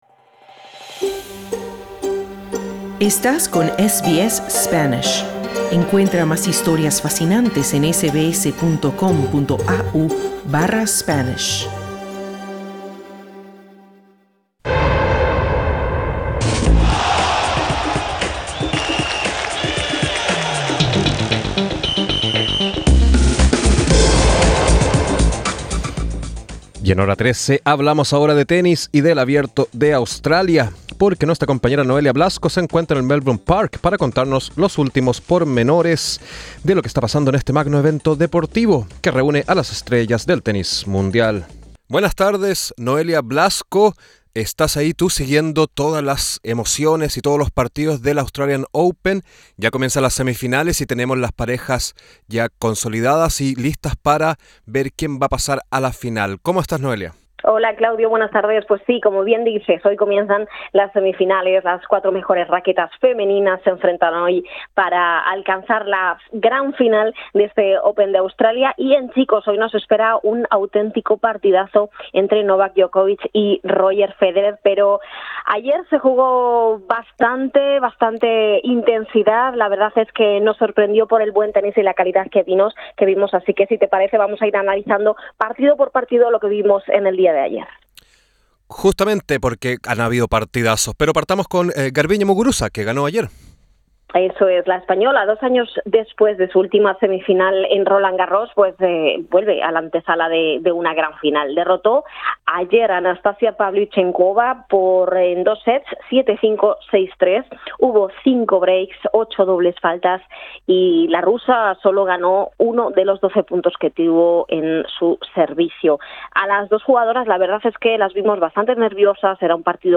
Esta noche se juega un partido de infarto entre Roger Federer y Novak Djokovic. Escucha nuestro informe del AO 2020.